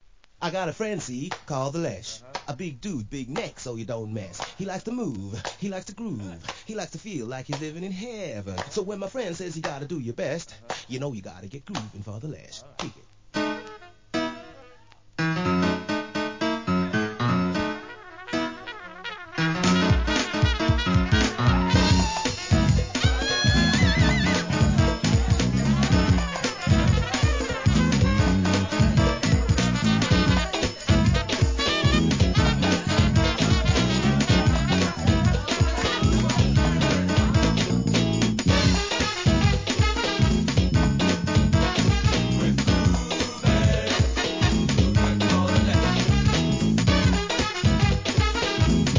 HIP HOP/R&B
両面ともに洒落オツなJAZZYトラックでオススメ!!